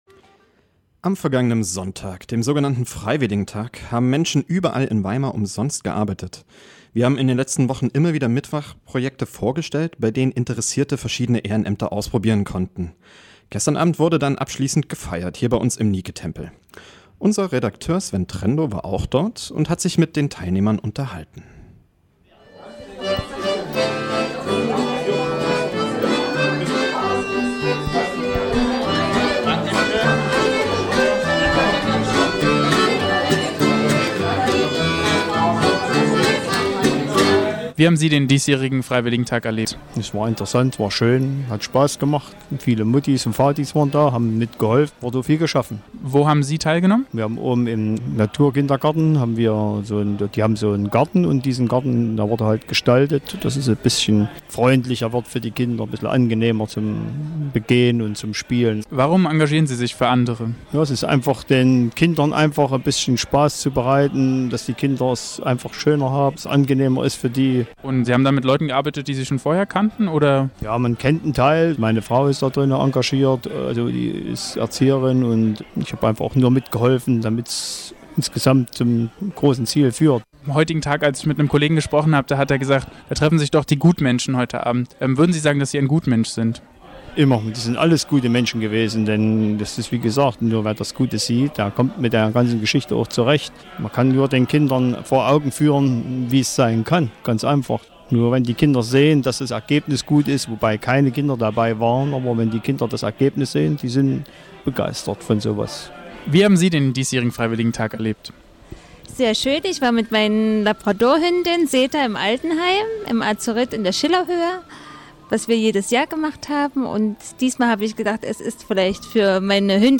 Neuer Ort, geänderter Tag - volles Haus.
Der große Sendesaal von Radio Lotte im Nike-Tempel bot den perfekten Rahmen für die gesellige Abschlussveranstaltung.